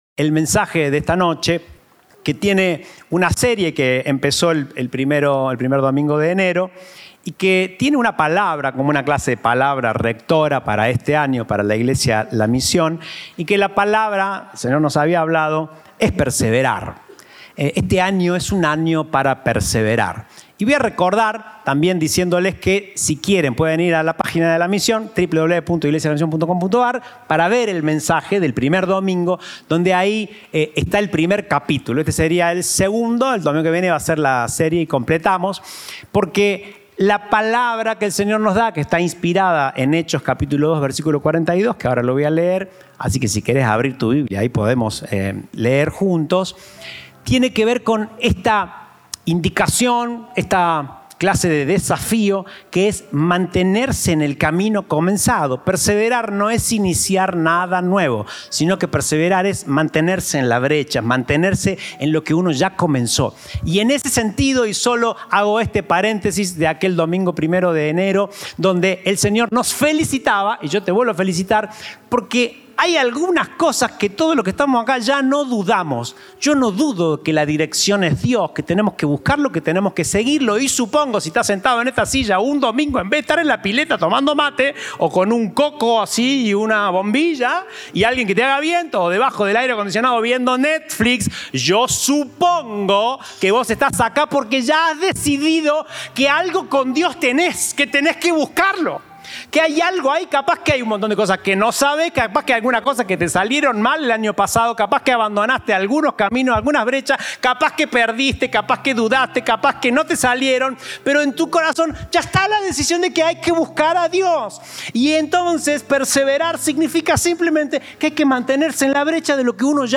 Compartimos el mensaje del Domingo 21 de Enero de 2024